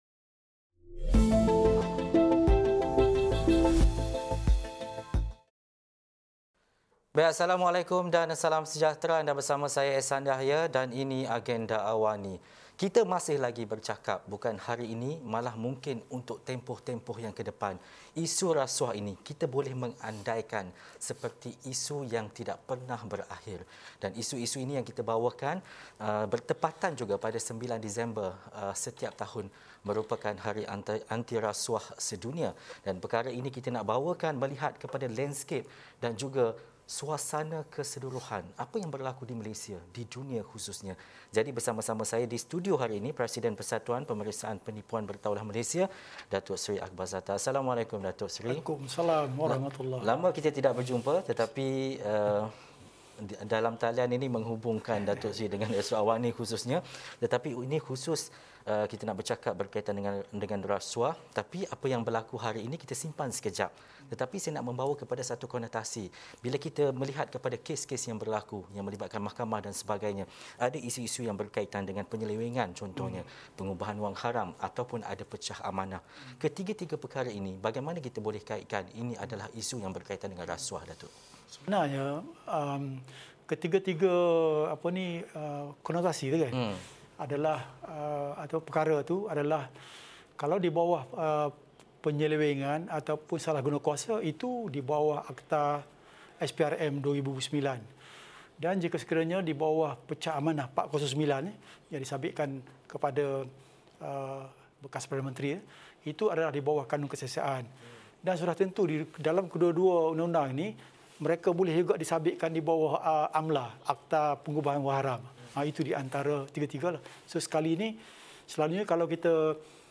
Temu bual